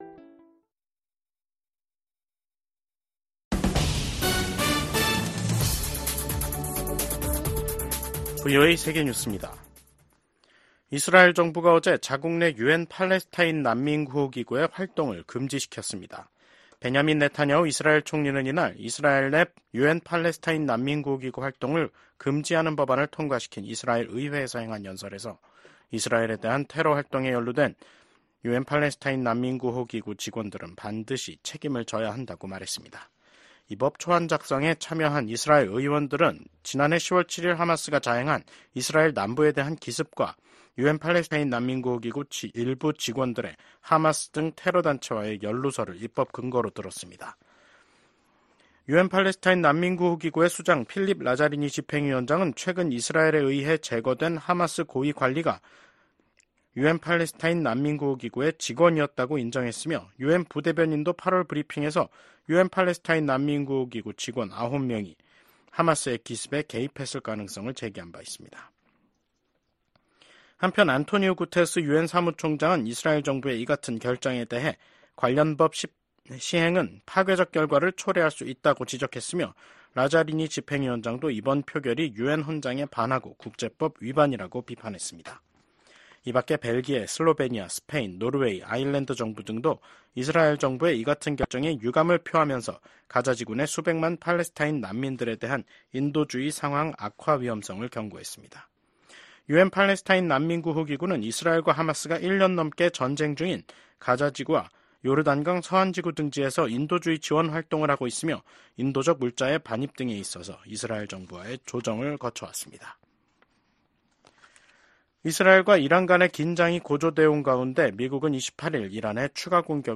VOA 한국어 간판 뉴스 프로그램 '뉴스 투데이', 2024년 10월 29일 2부 방송입니다. 한국 정보 당국은 러시아에 파병된 북한군 중 고위급 장성을 포함한일부 병력이 전선으로 이동했을 가능성이 있다고 밝혔습니다. 미 국방부가 북한군 1만명이 러시아의 우크라이나 전쟁을 지원하기 위해 파병됐다는 사실을 확인했습니다.